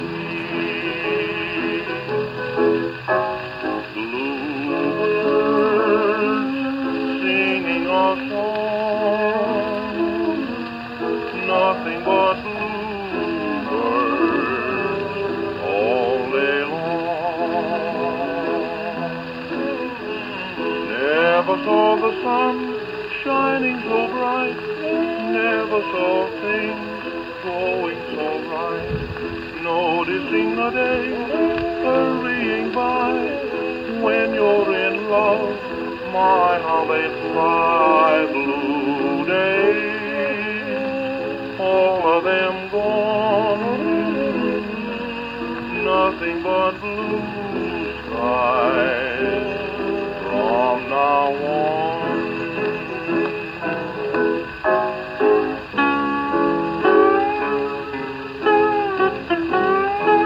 Orthophonic Credenza with Brass Reproducer for sale
A favorite of record collectors who want to hear their 1920s 78s exactly as they were intended to be heard, the Orthophonic Victrola represents the apex of development of the acoustic talking machine. Victor purchased patents from Western Electric for a horn which folded over itself increasing in exponential proportions -- this and the new process of electrical recording deepened the frequency range by about two octaves, yielding a rich, impressive bass heretofore unheard in acoustic machines.
CredenzaWithBrass.mp3